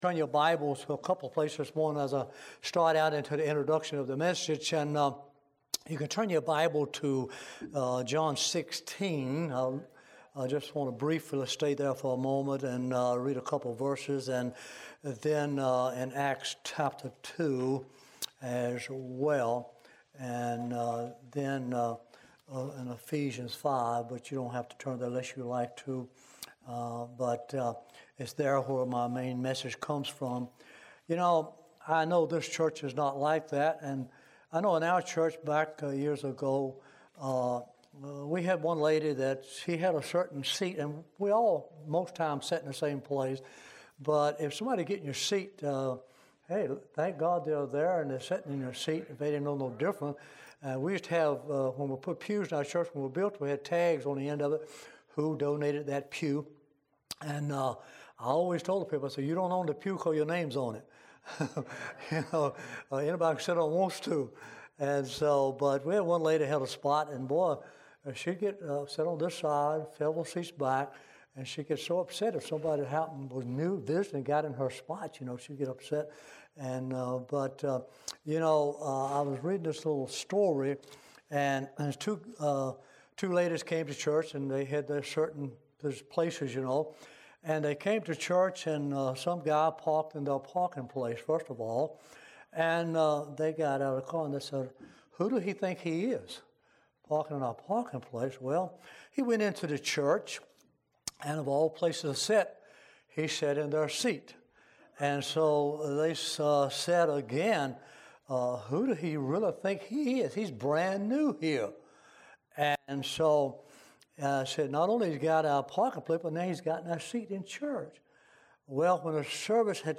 Ephesians 5 Service Type: Sunday AM « The Intimacy Of God Filled With the Holy Spirit